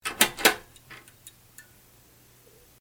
有关开灯音效的演示模板_风云办公